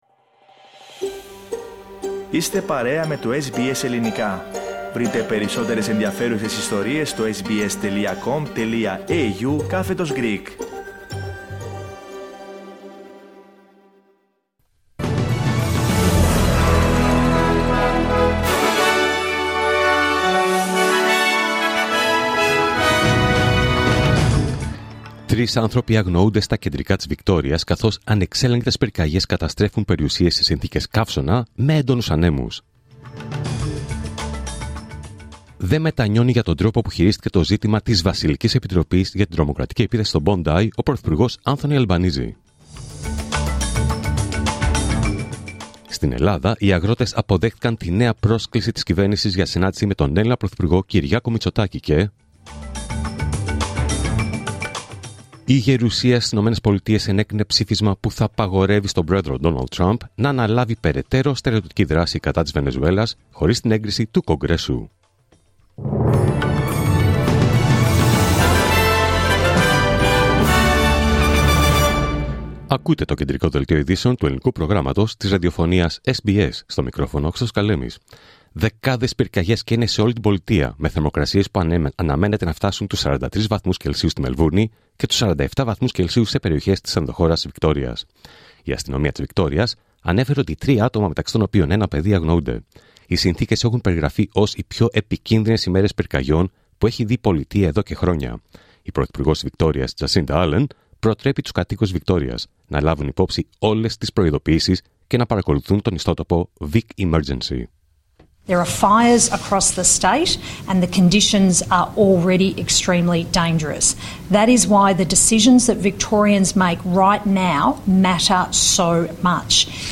Δελτίο Ειδήσεων Παρασκευή 9 Ιανουαρίου 2026